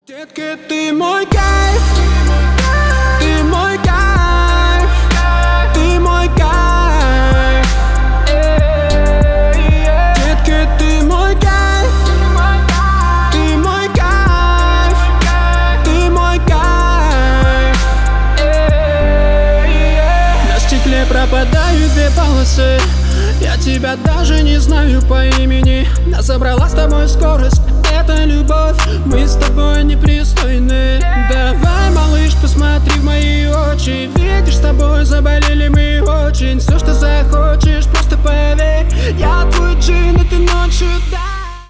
Поп Музыка
грустные # кавер